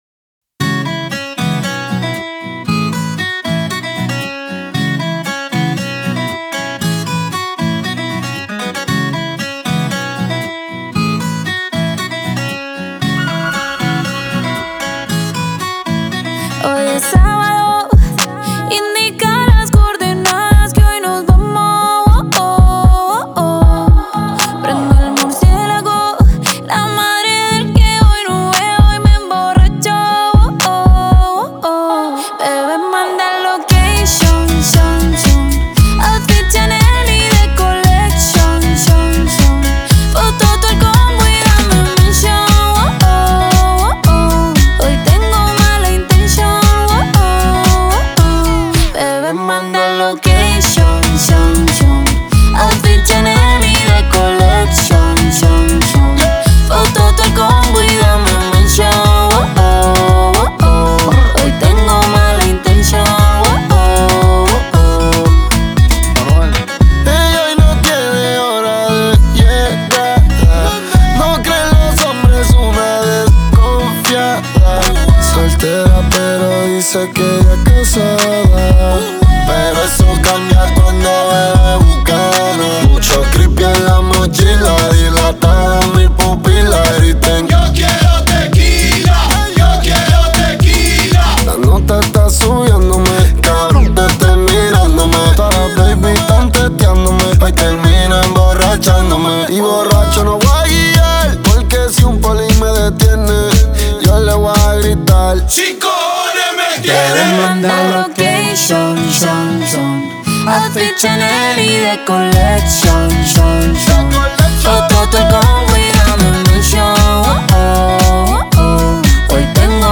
это зажигательный трек в жанре реггетон